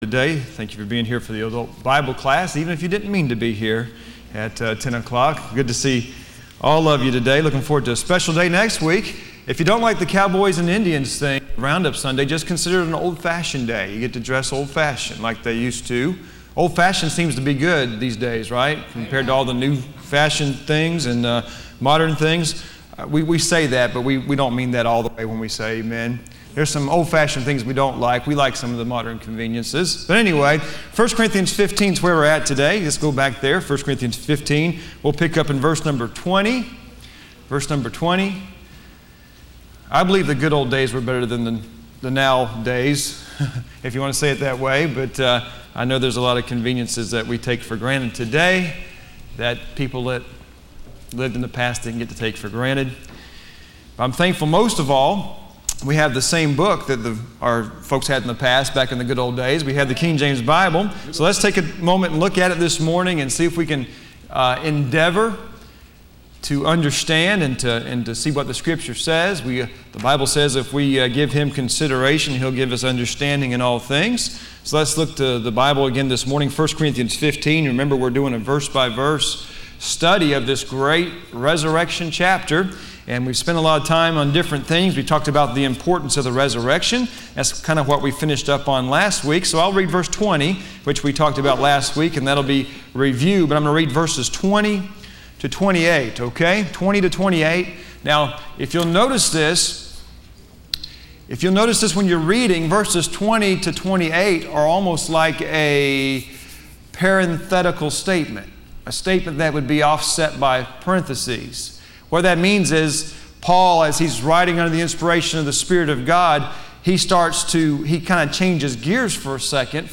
Listen to Message
Service Type: Sunday School